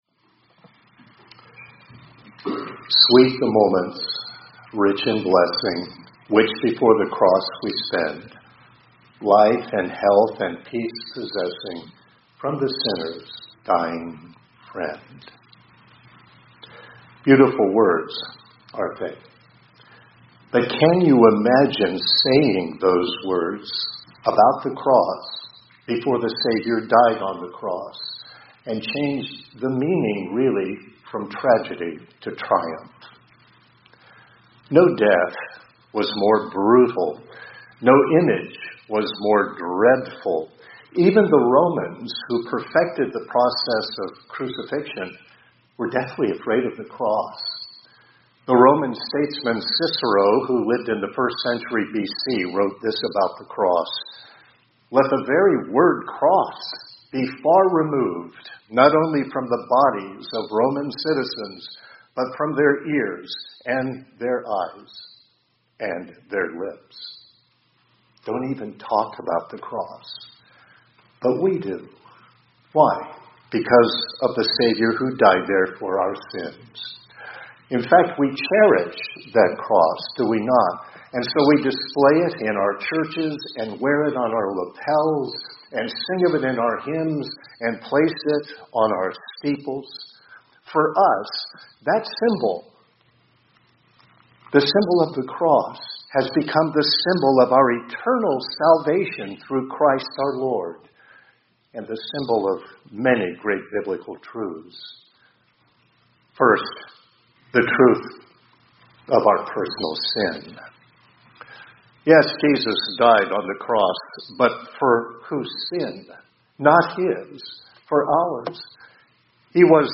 2025-04-15 ILC Chapel — In View of the Cross